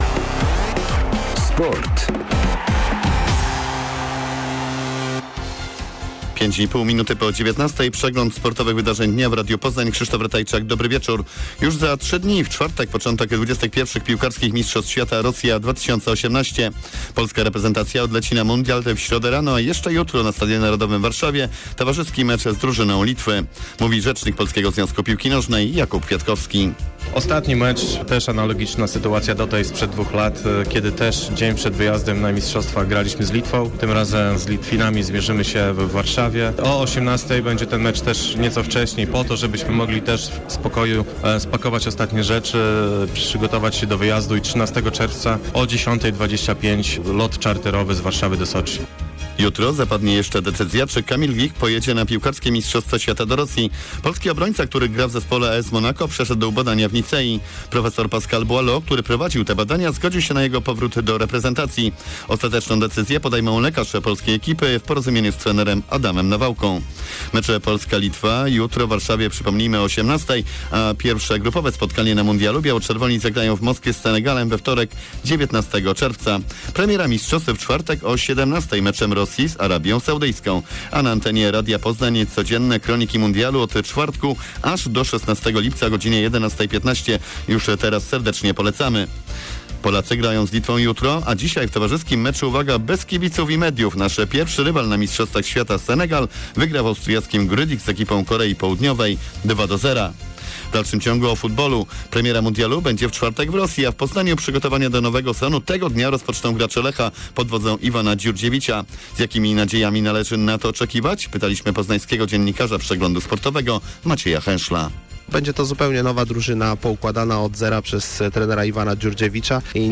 11.06 serwis sportowy godz. 19:05